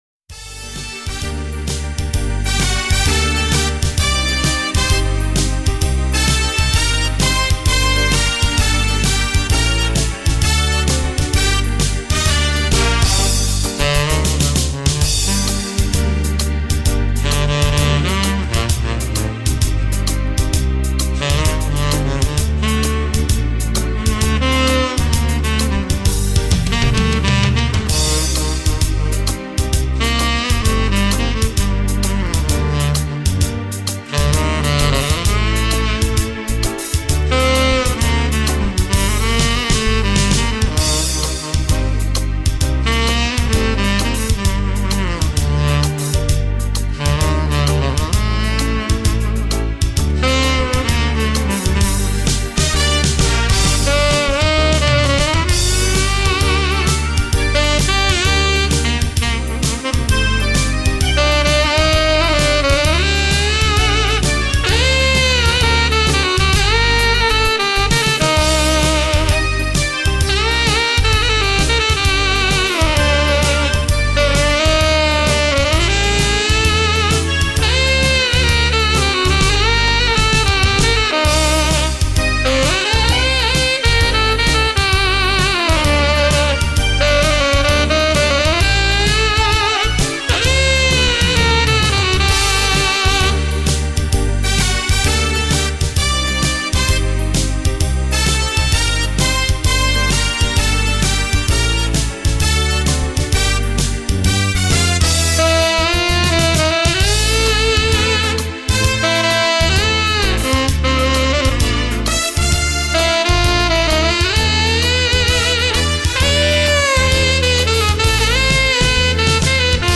и прекрасный саксофон